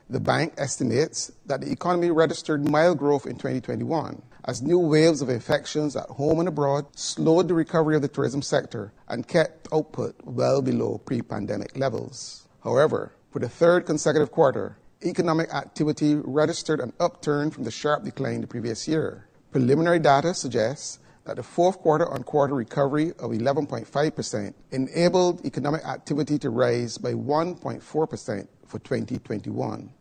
Delivering the economic review for 2021, Central Bank Governor Cleviston Haynes said that provided there isn’t another shut down of global travel, the economic recovery should pick up pace in 2022.